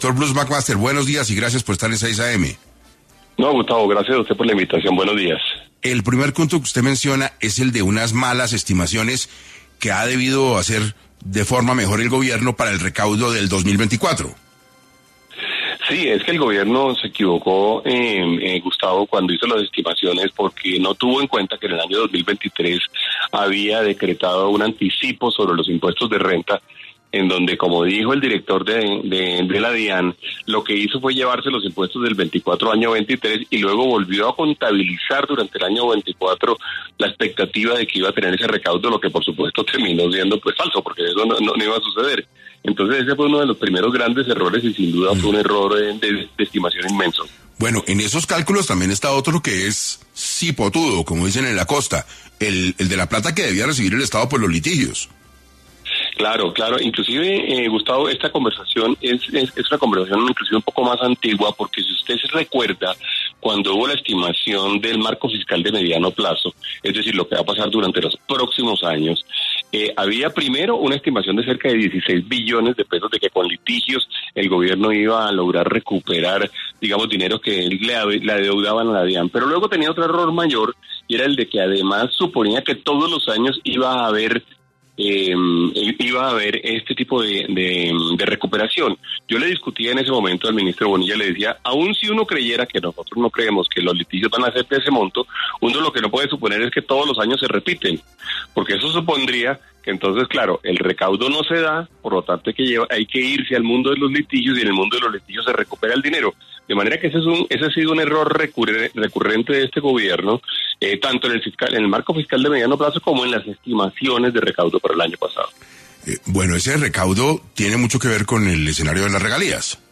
Bruce Mac Máster, presidente de la ANDI, hablo en 6AM sobre la salida de Jairo Orlando Villabona de la dirección de la DIAN